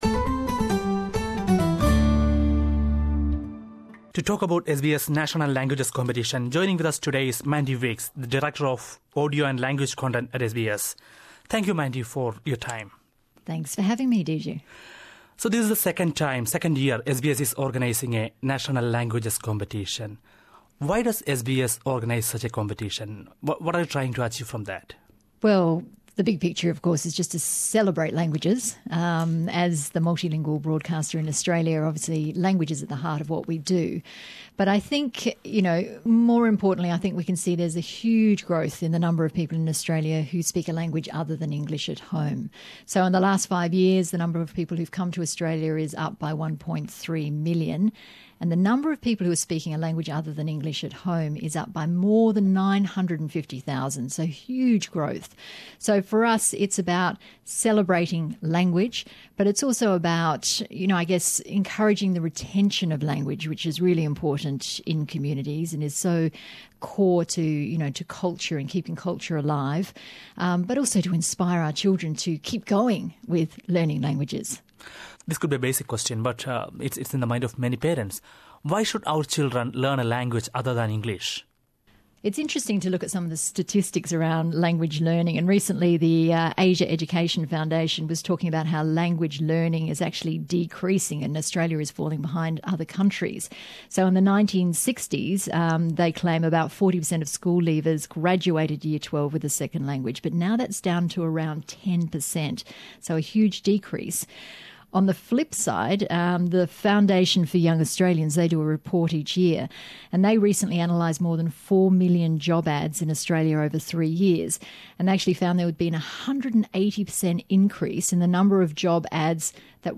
Roja dawiyê bo pêshkêshkirina forman 1 meha Êlûnê ye. Hevpeyvîneke